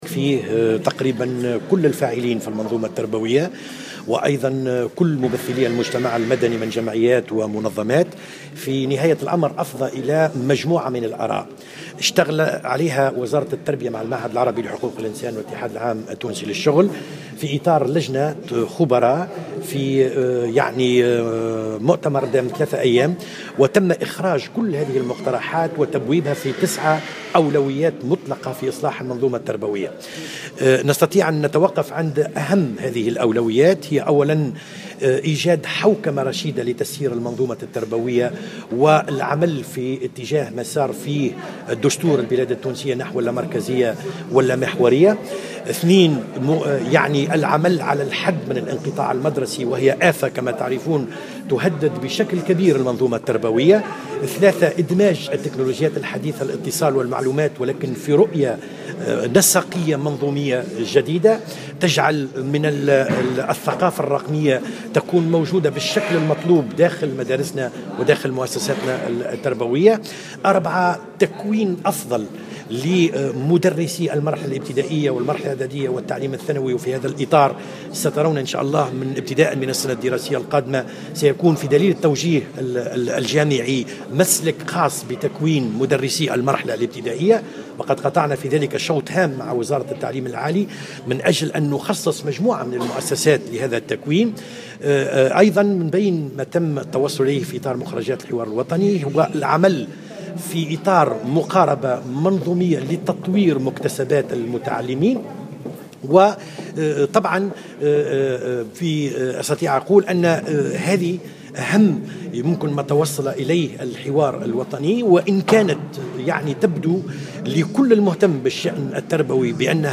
أكد مدير عام المرحلة الابتدائية بوزارة التربية كمال الحجام في تصريح لمراسل الجوهرة "اف ام" اليوم الاثنين 22 فيفري 2016 أن الحوار الوطني الذي شارك فيه كل الفاعلين في المنظومة التربوية أفضى إلى مجموعة من الآراء التي عملت وزارة التربية بالتنسيق مع المعهد العربي لحقوق الإنسان واتحاد الشغل على صياغتها في اطار لجنة خبراء في مؤتمر دام 3 أيام وتم إخراج كل هذه المقترحات و تبويبها في 9 أولويات مطلقة في إصلاح المنظومة التربوية .